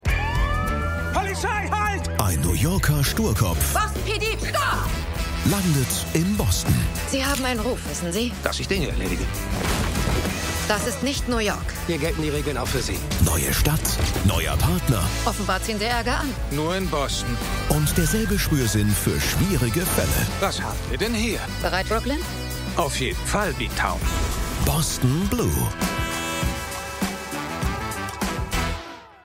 Sky-Trailer zu BOSTON BLUE
BostonBlue_SkyTrailer.mp3